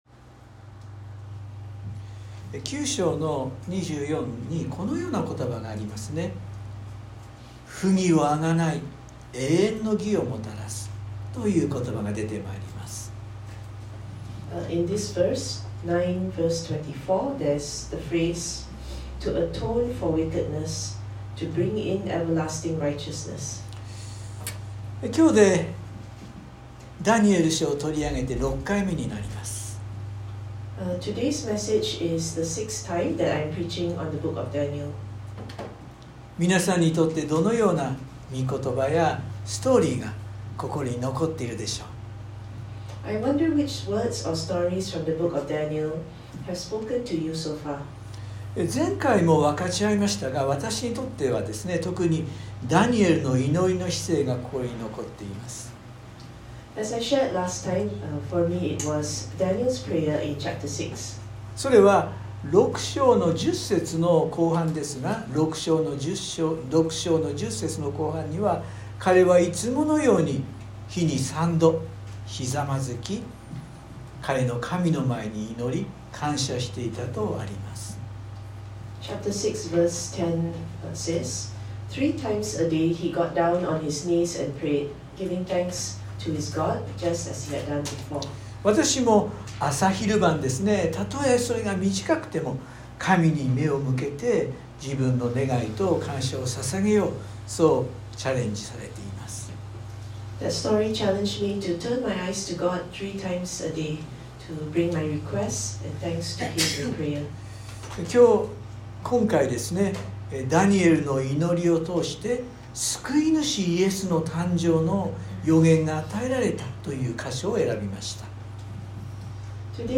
（日曜礼拝録音） 【iPhoneで聞けない方はiOSのアップデートをして下さい】 今日でダニエル書を取り上げて、６回目になります。